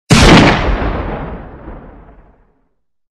جلوه های صوتی
دانلود صدای تفنگ دوربین دار از ساعد نیوز با لینک مستقیم و کیفیت بالا